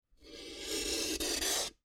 Metal_102.wav